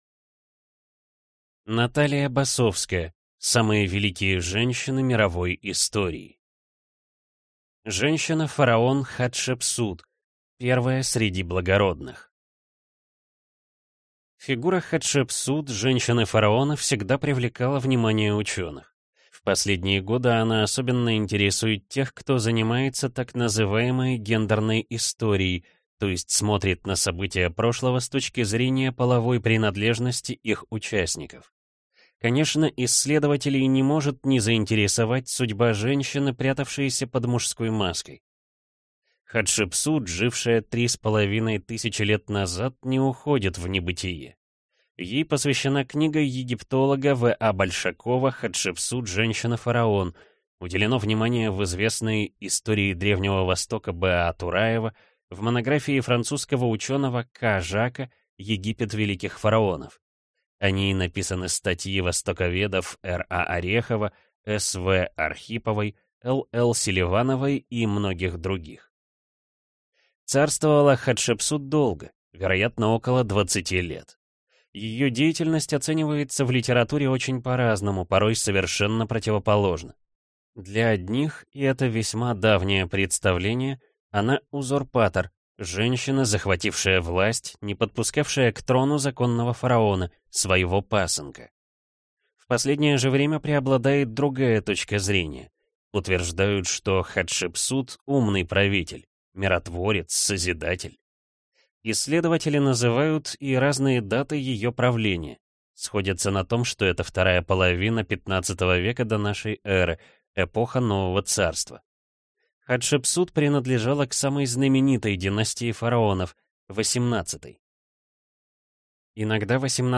Аудиокнига Самые великие женщины мировой истории | Библиотека аудиокниг